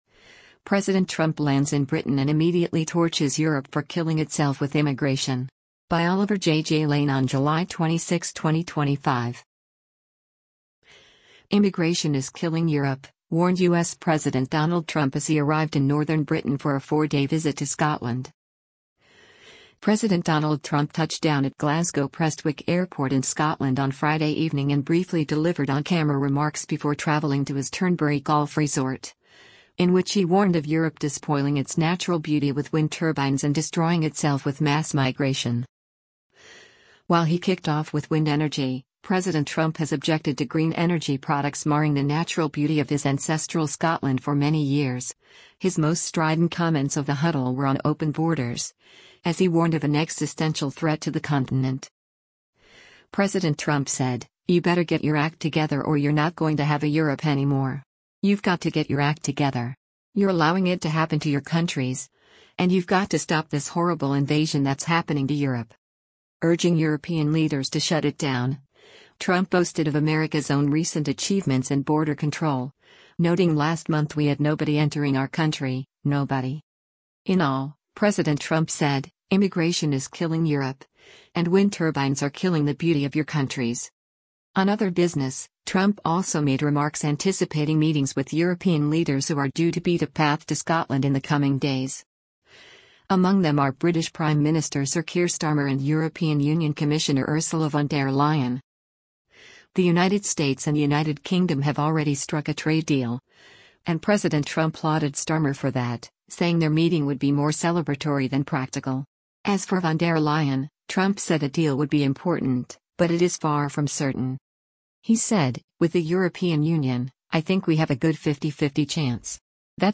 PRESTWICK, UNITED KINGDOM - JULY 25: U.S. President Donald Trump speaks to the media as he
President Donald Trump touched down at Glasgow Prestwick Airport in Scotland on Friday evening and briefly delivered on-camera remarks before travelling to his Turnberry golf resort, in which he warned of Europe despoiling its natural beauty with wind turbines and destroying itself with mass migration.